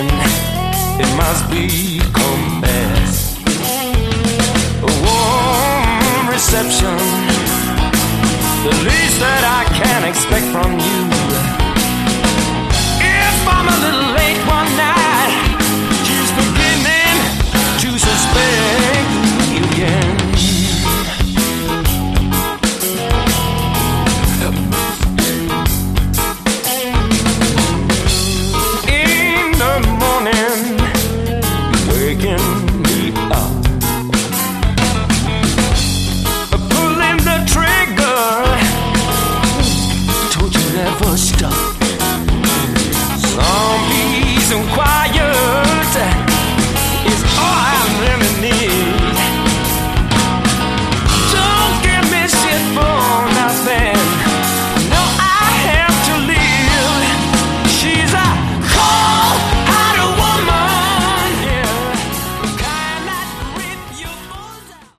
Category: Melodic Hard Rock
Vocals
Guitars
Bass
Drums
Keyboards